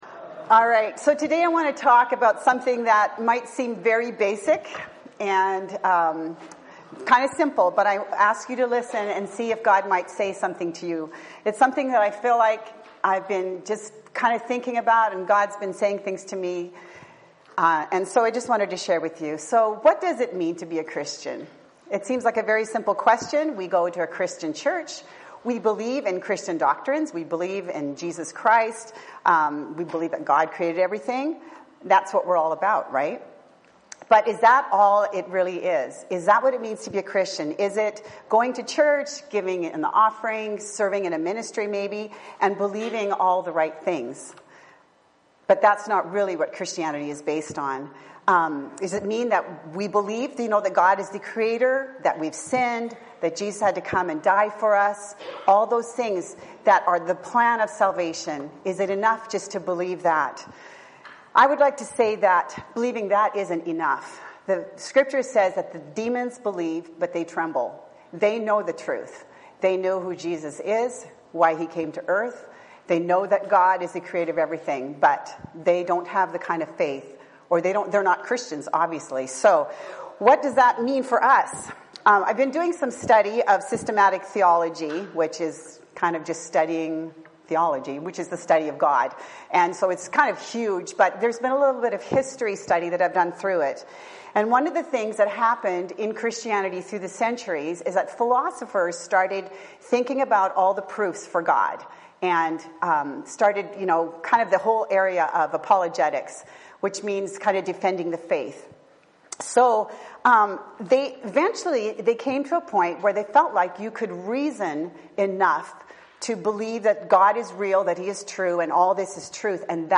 Bible Text: Philippians 3:7-9 | Preacher